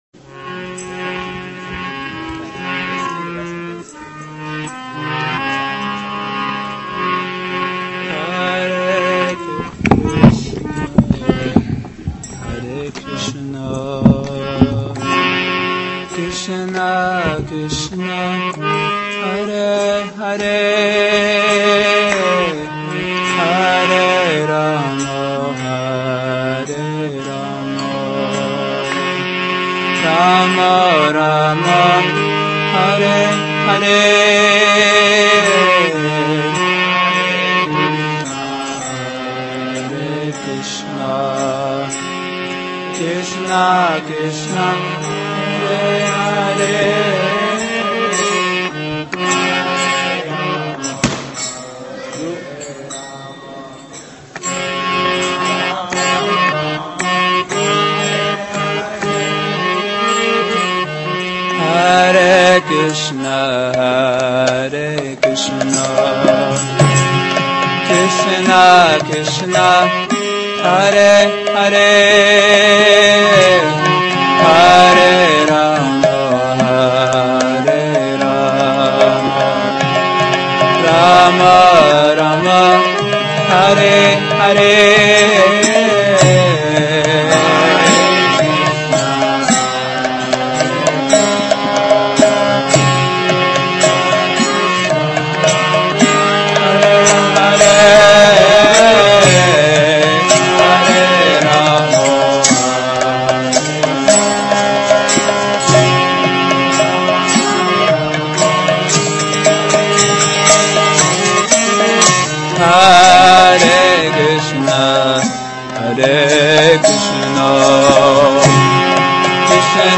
Hare Krsna Kirtana